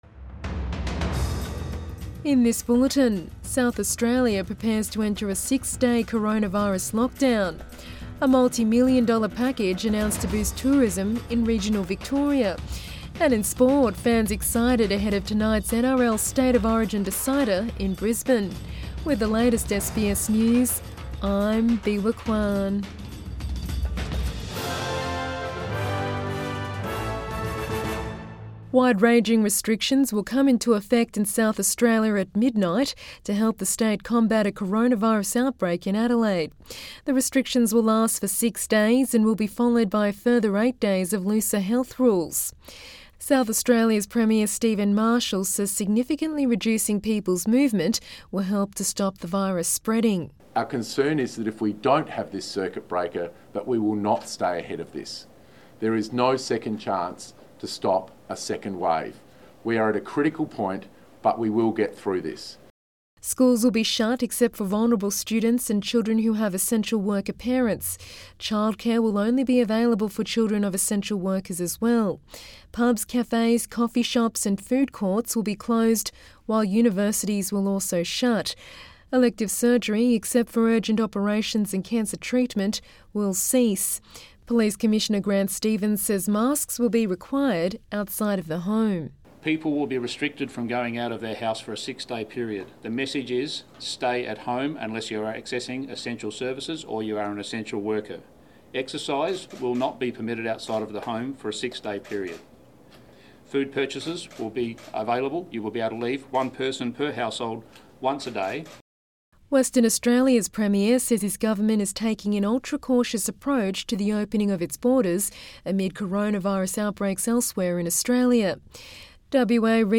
PM bulletin 18 November 2020